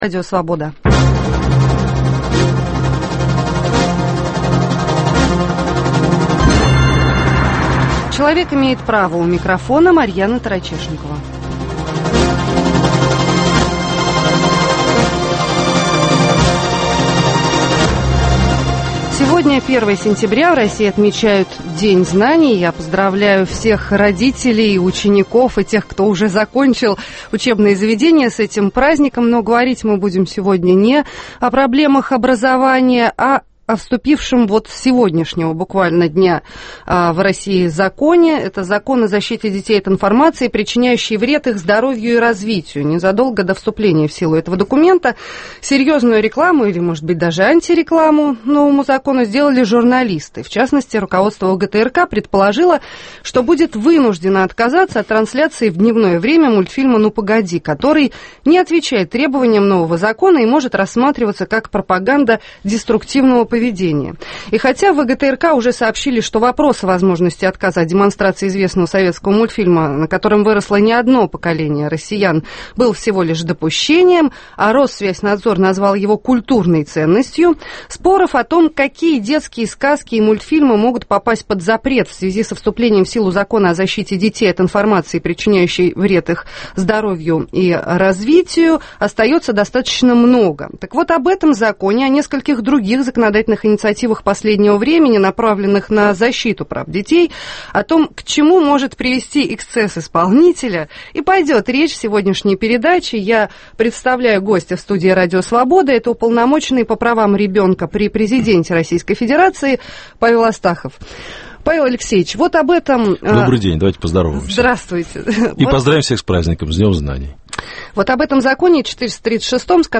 В студии РС Уполномоченный по правам ребенка при президенте РФ Павел Астахов.